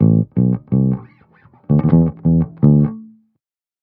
01 Bass Loop A.wav